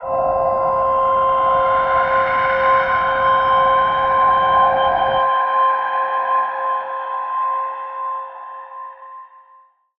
G_Crystal-C6-f.wav